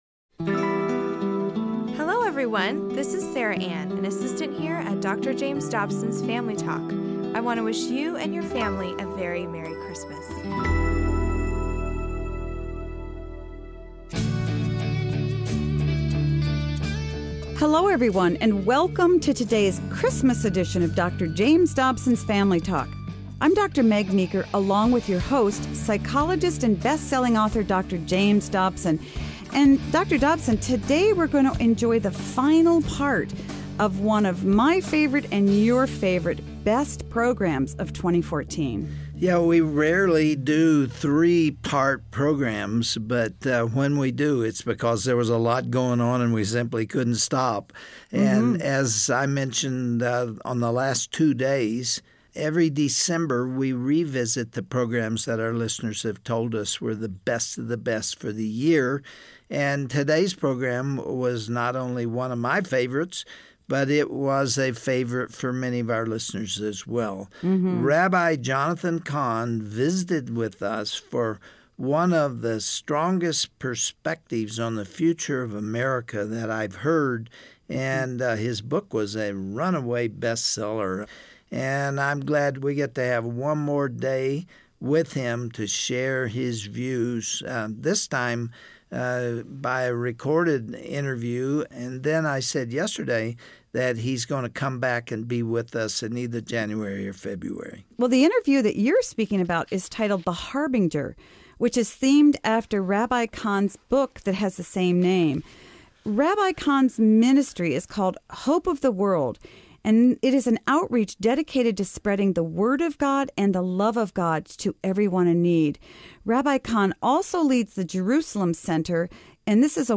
On the next edition of Family Talk, Dr. James Dobson will interview Rabbi Jonathan Cahn about the future of America.